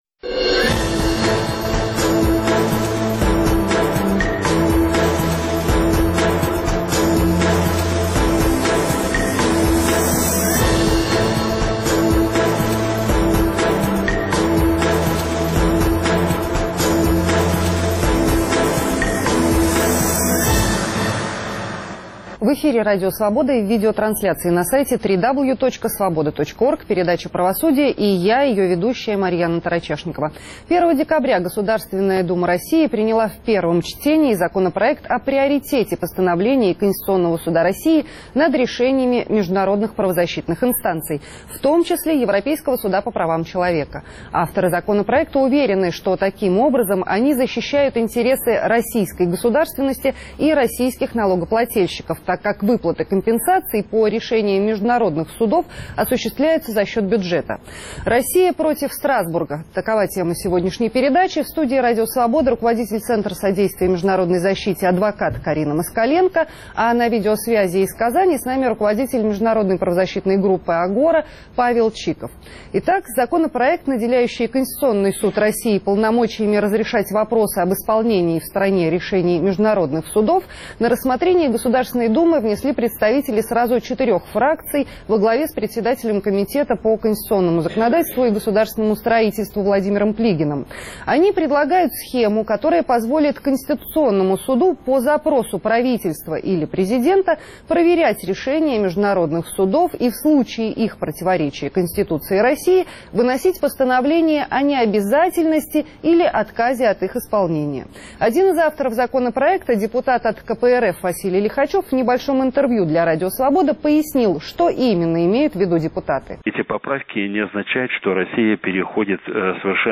В студии Радио Свобода
на видеосвязи из Казани